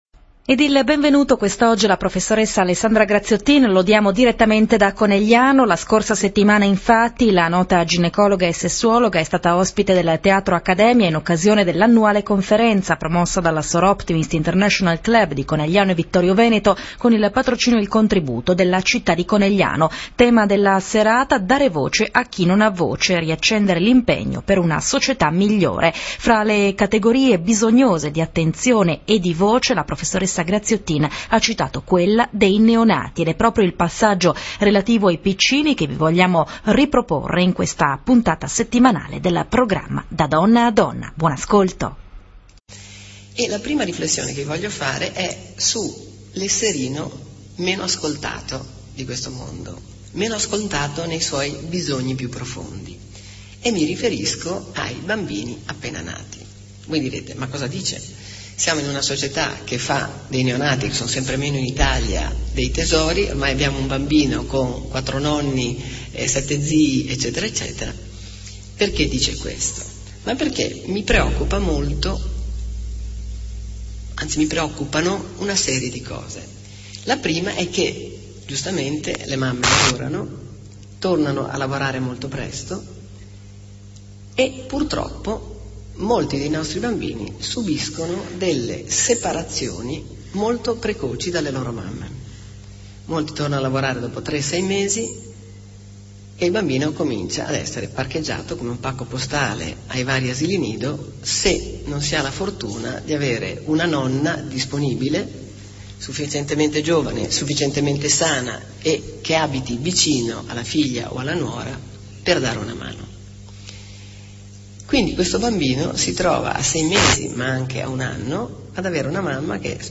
conferenza e introduzione.mp3